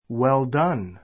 well-done wel dʌ́n ウェ る ダ ン 形容詞 ❶ 立派に行われた, よくできた Well done!